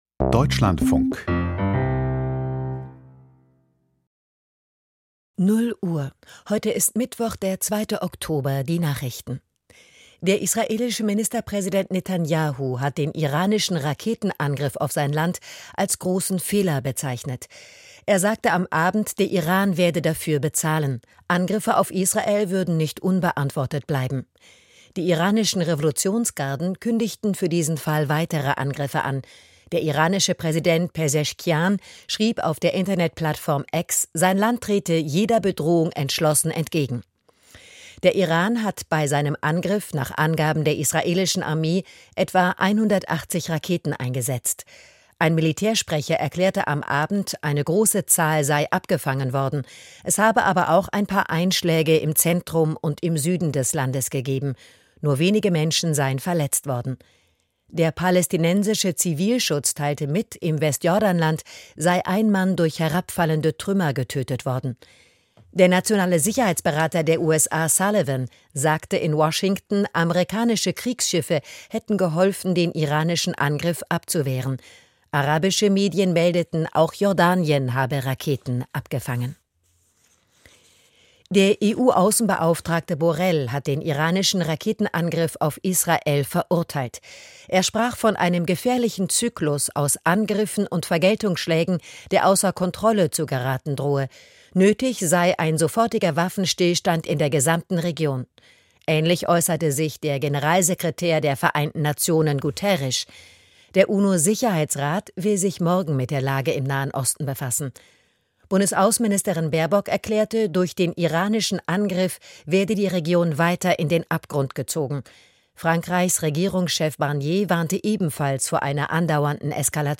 Die Deutschlandfunk-Nachrichten vom 01.10.2024, 23:59 Uhr